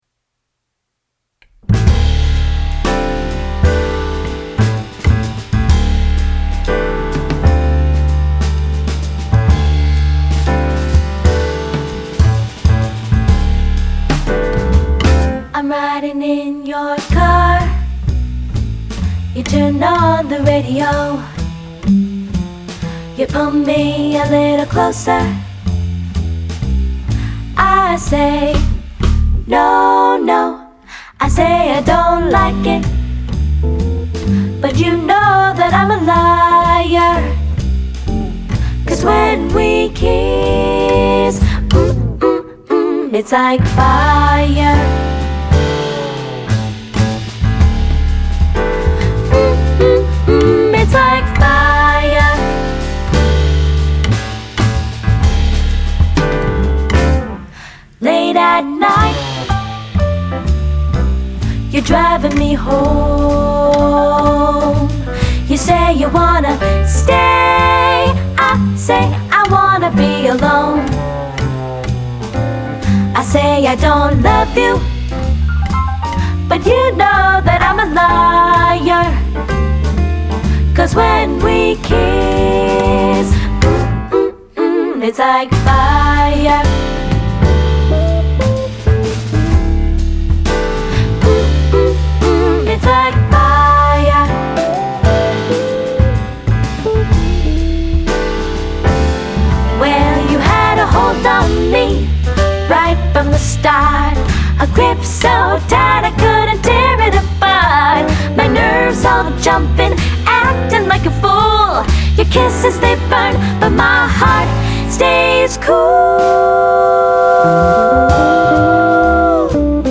Toronto-based vocal jazz trio